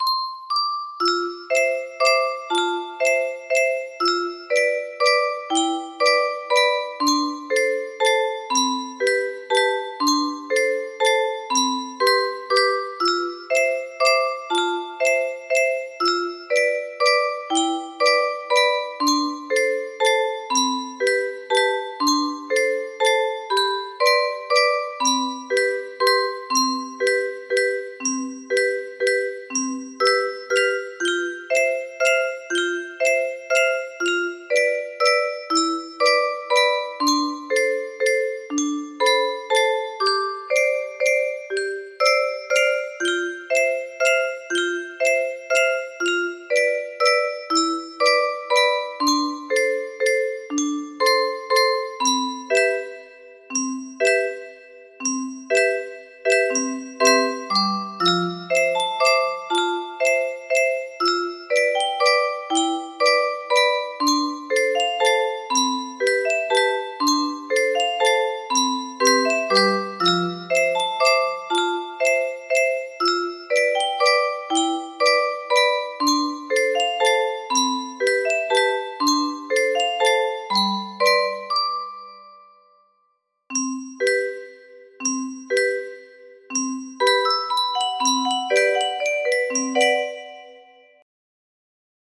Fish in a pool music box melody
Grand Illusions 30 (F scale)